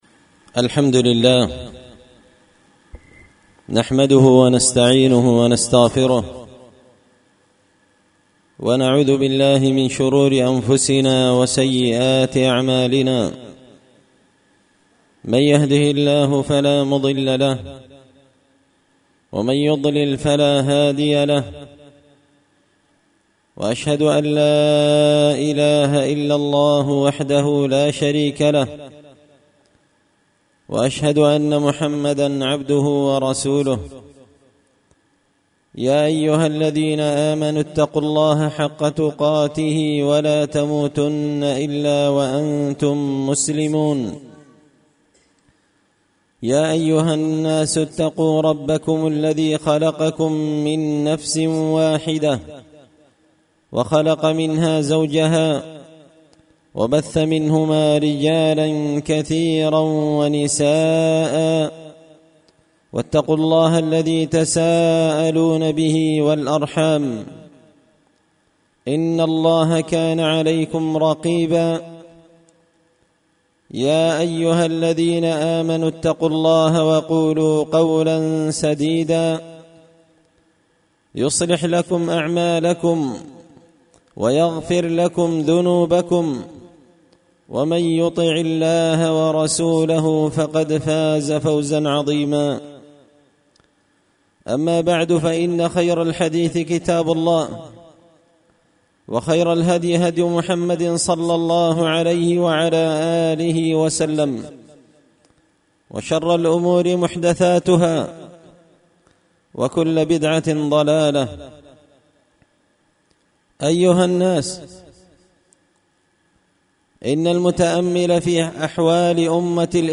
خطبة جمعة بعنوان – الاختلاف والزلل سبب الهزيمة والفشل
دار الحديث بمسجد الفرقان ـ قشن ـ المهرة ـ اليمن